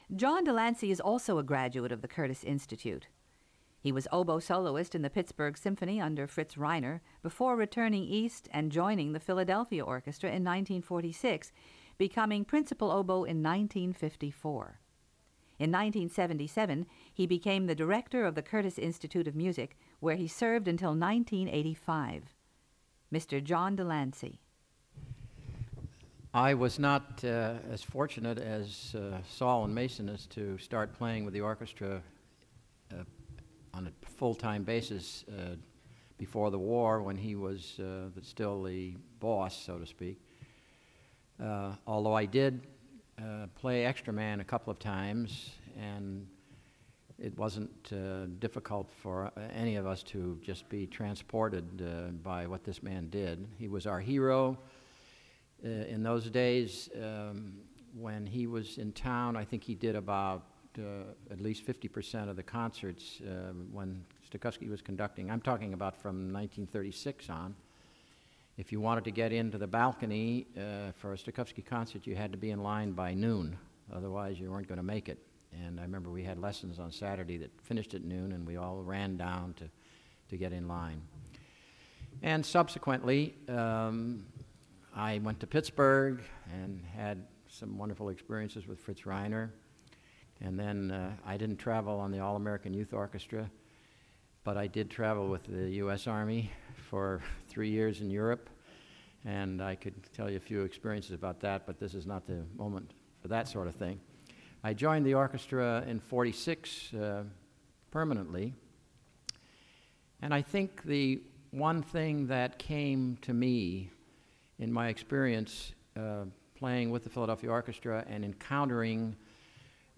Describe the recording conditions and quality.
A presentation made as a part of the symposium "Leopold Stokowski: Making Music Matter," University of Pennsylvania, 15 April 1998. Transferred from cassette tape.